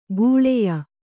♪ gūḷeya